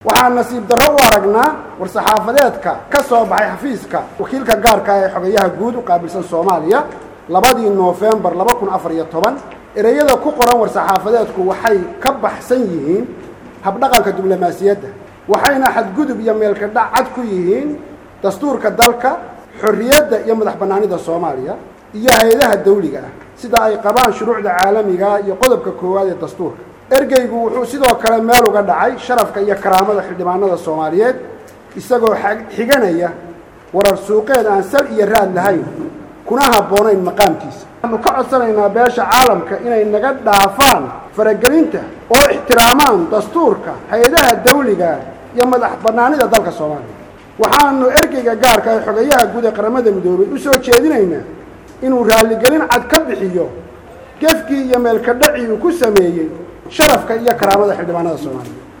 DHAGEYSO: WARSAXAAFADEEDKA XILDHIBAANNADA SOMALIA